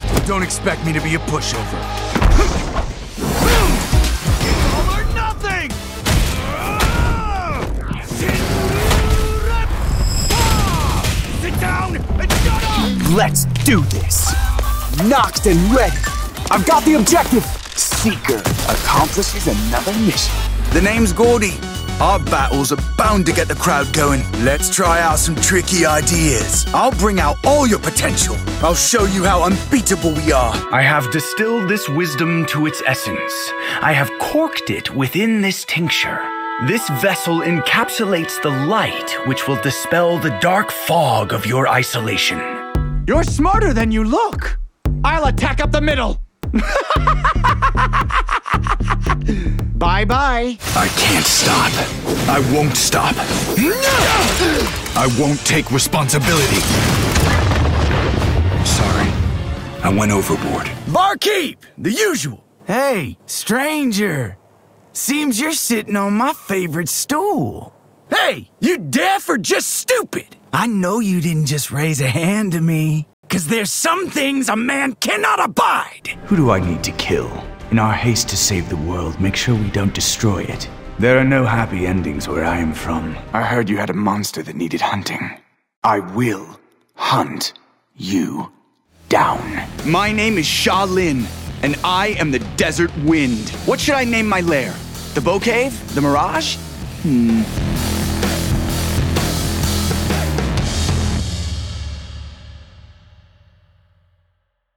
• Character Voices